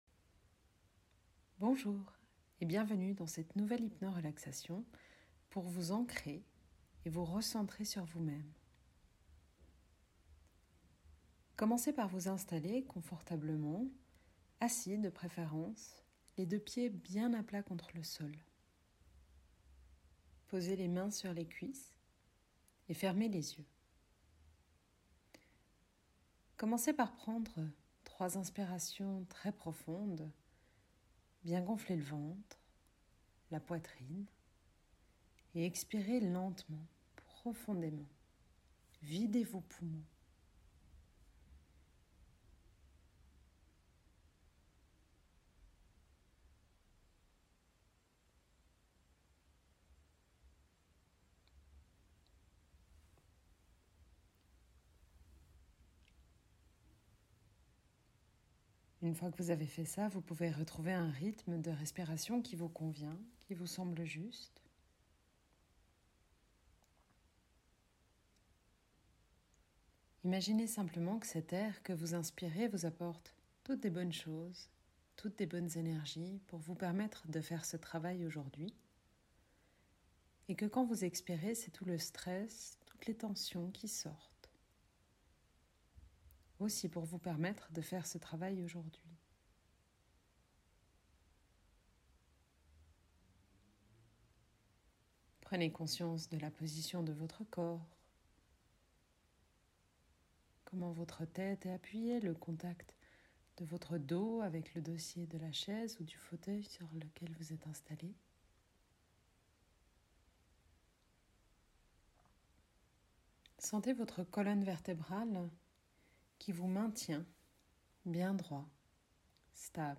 Dans cette hypno-relaxation, je vous propose un ancrage pour retrouver le calme et la sérénité, retrouver de l’énergie.
Hypno-relaxation_Ancrage.mp3